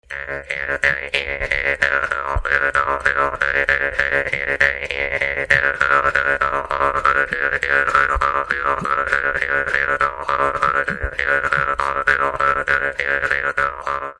Dallampélda: Hangszeres felvétel
Moldva és Bukovina - Moldva - Klézse
doromb Műfaj: Kecskés